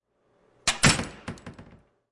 敲门
描述：敲我的门，打开它。
标签： 近距离
声道立体声